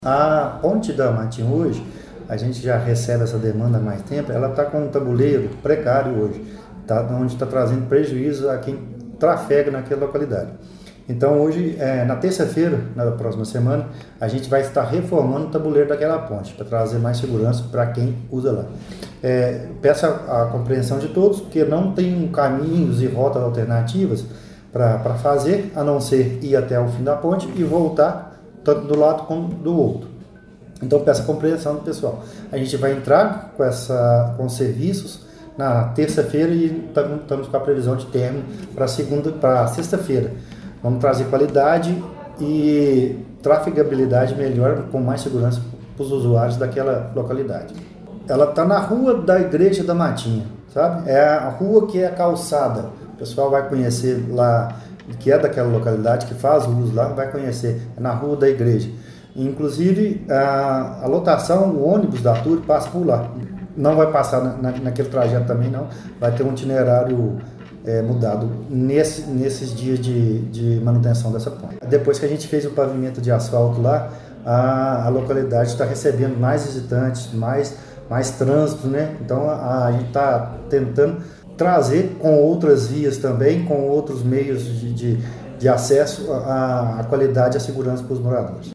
A intervenção consistirá na troca completa do tabuleiro da ponte. O secretário destacou que a nova estrutura será reforçada para suportar o aumento da demanda de tráfego, que cresceu depois que a rua principal do bairro foi asfaltada: